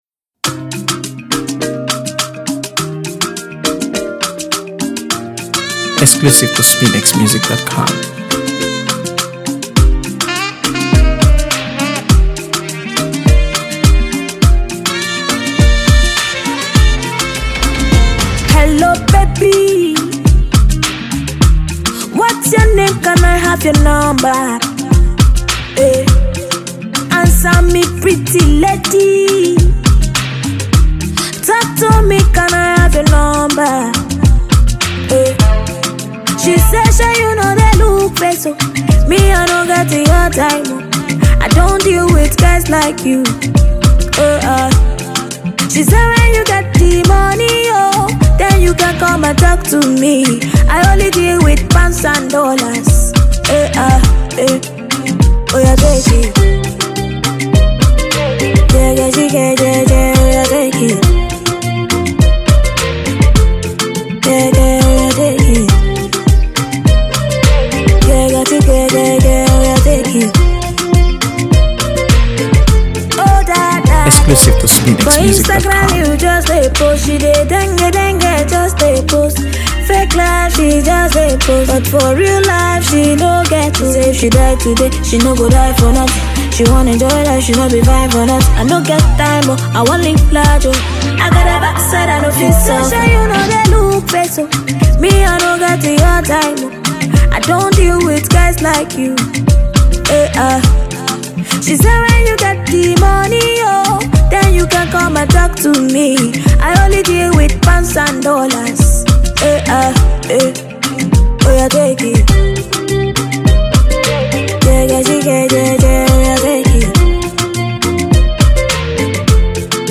AfroBeats | AfroBeats songs
Mid-Tempo Track
captivating mid-tempo track
” delighting fans with her smooth vocals and unique style.